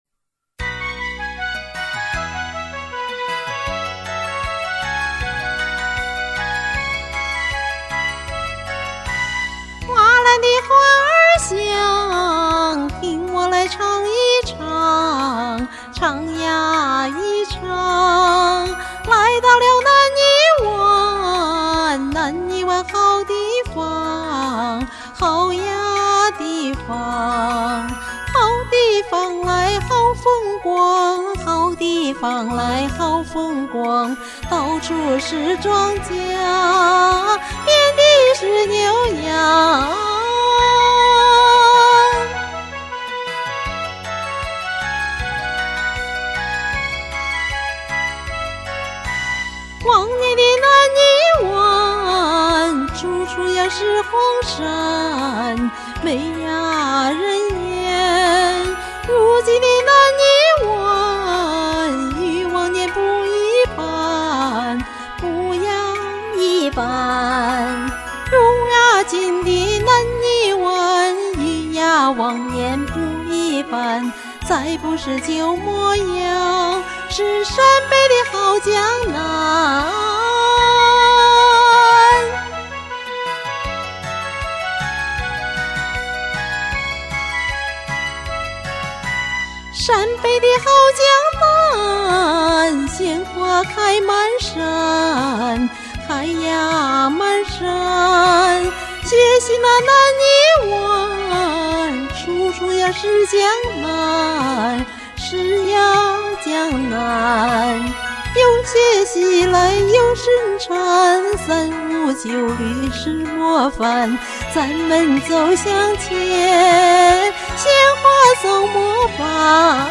《南泥湾》--好听的老红歌
《南泥湾》 贺敬之 词  马可 曲